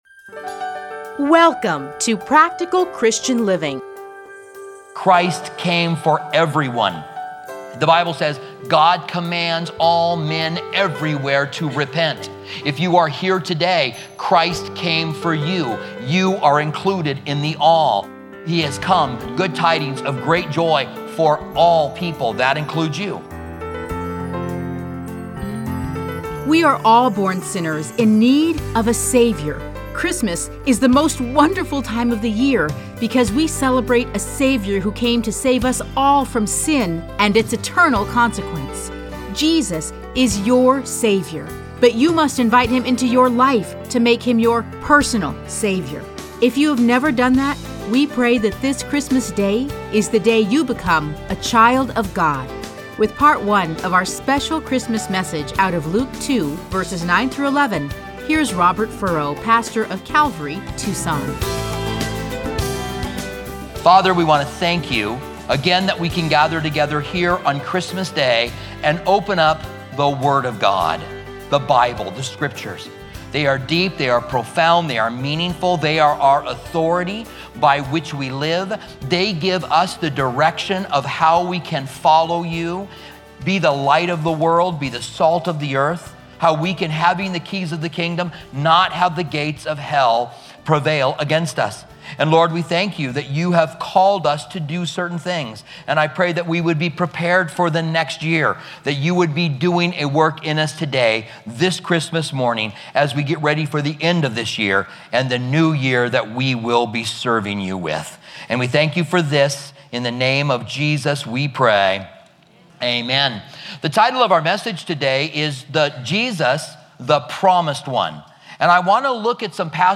Listen to a teaching from Luke 1-2.